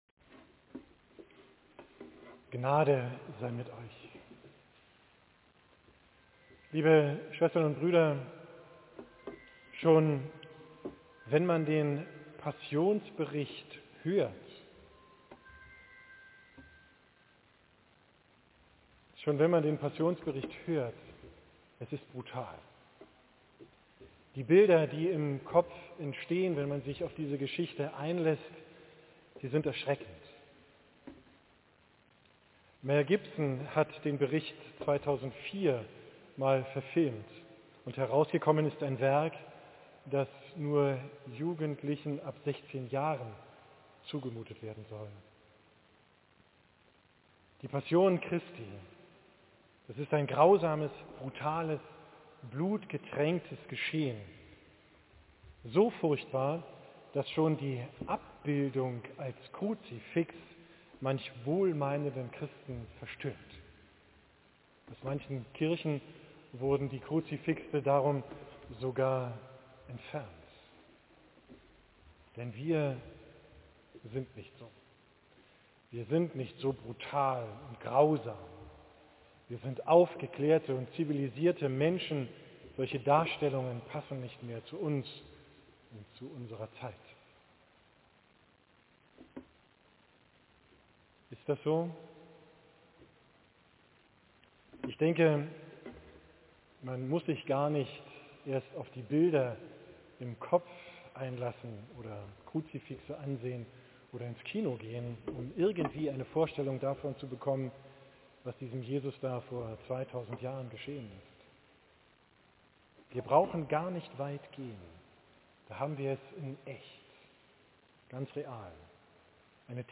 Predigttext: 1 Am Morgen aber hielten alle Hohenpriester und die Ältesten des Volkes einen Rat über Jesus, dass sie ihn töteten, 2 und sie banden ihn, führten ihn ab und überantworteten ihn dem Statthalter Pilatus. 3 Als Judas, der ihn verraten hatte, sah, dass er zum Tode verurteilt war, reute es ihn, und er brachte die dreißig Silberlinge den Hohenpriestern und Ältesten zurück 4 und sprach: Ich habe gesündigt, unschuldiges Blut habe ich verraten.